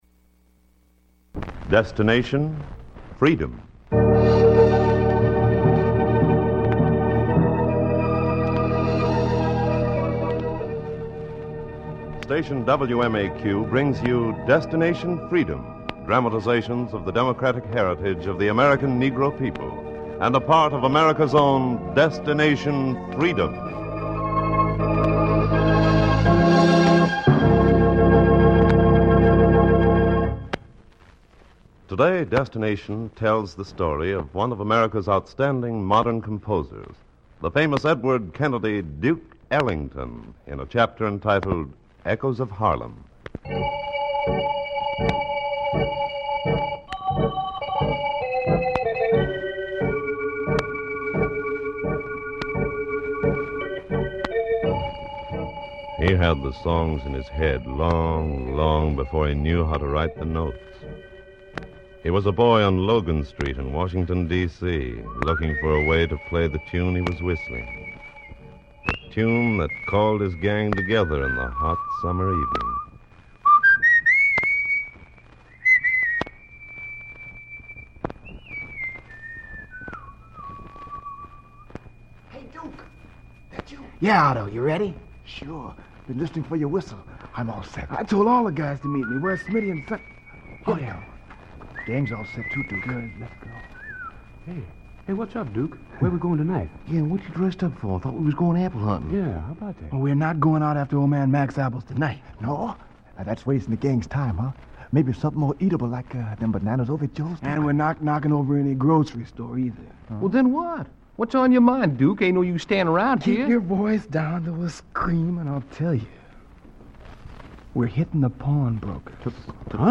"Destination Freedom" was a pioneering radio series written by Richard Durham that aired from 1948 to 1950. The series aimed to highlight the achievements and struggles of African Americans, often focusing on historical figures and events that were underrepresented in mainstream media.